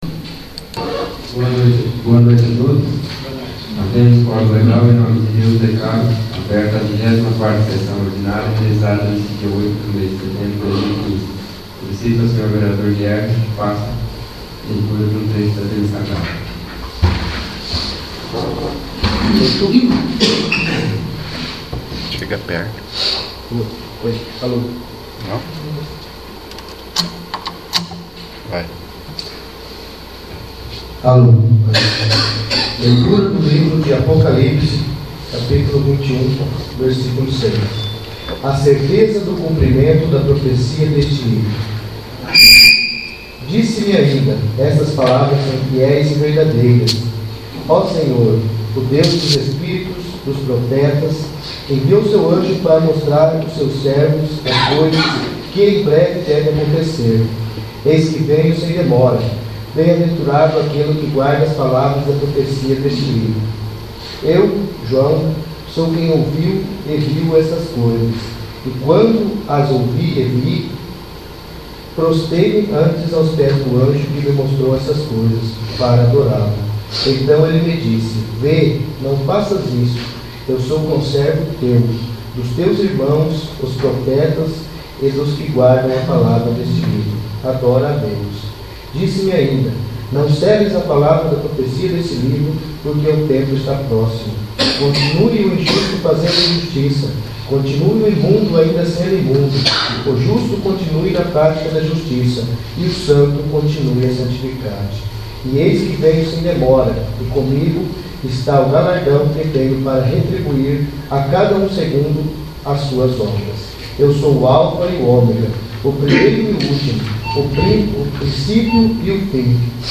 24º. Sessão Ordinária